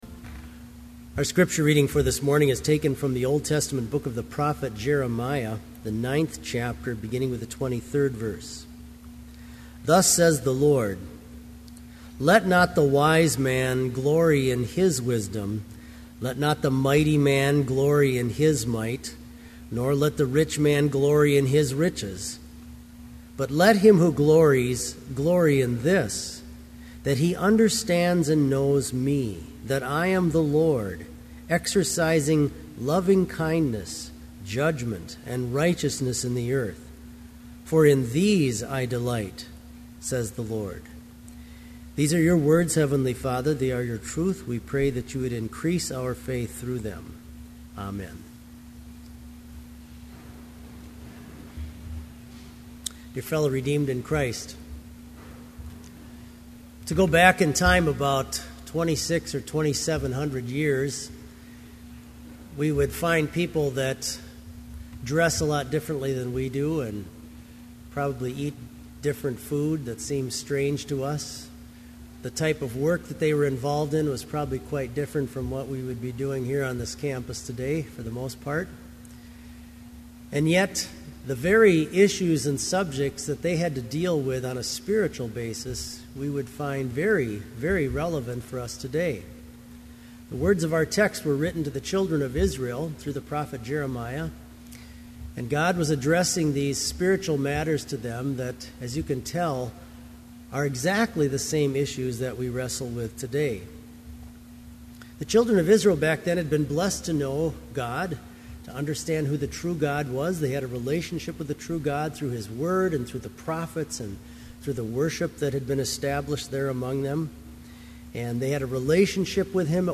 Complete service audio for Summer Chapel - June 13, 2012